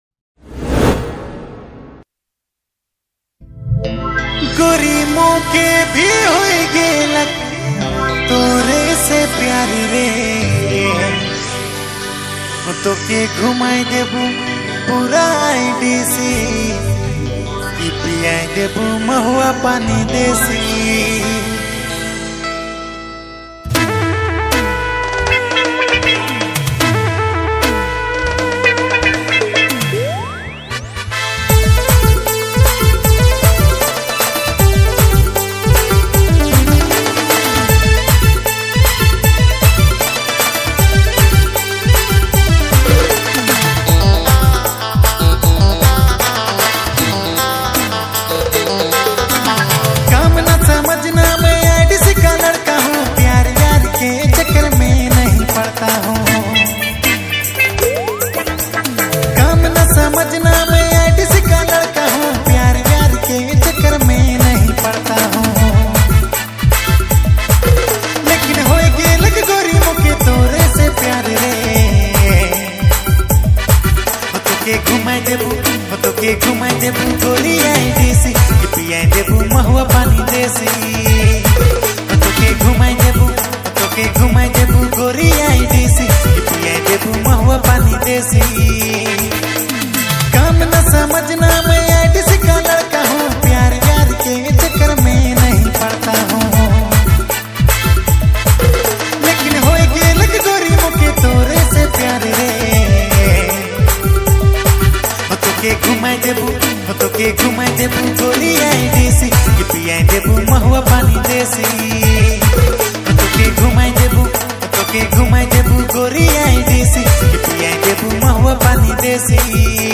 New Nagpuri All Singer Songs